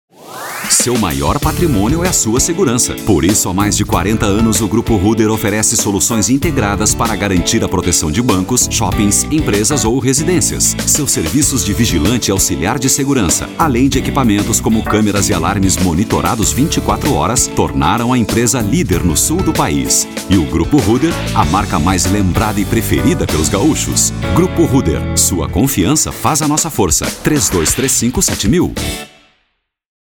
Locutor voz Grave, a disposição para gravação de Spots de Rádio e TV, bem como Esperas Telefônicas e documentários
• spot